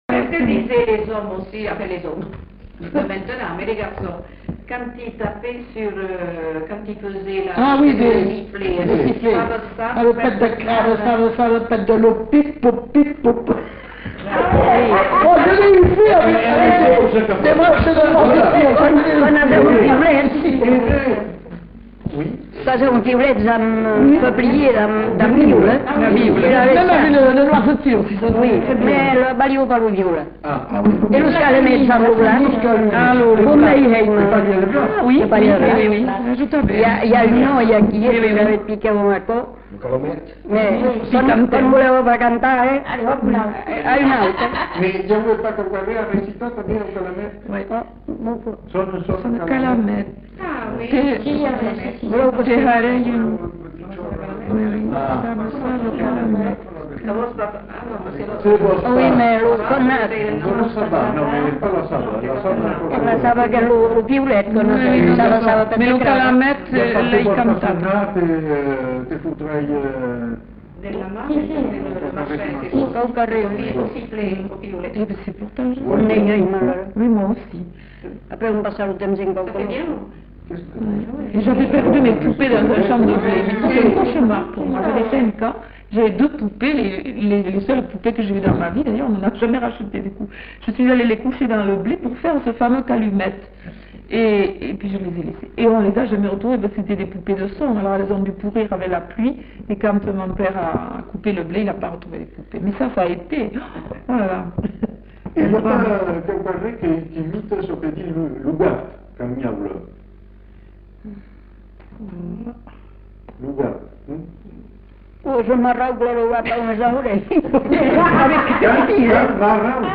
Lieu : Villandraut
Genre : forme brève
Type de voix : voix de femme
Production du son : récité
Classification : formulette enfantine